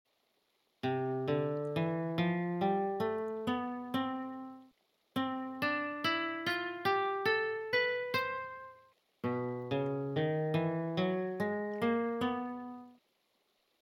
Basic_Lute_Sound.mp3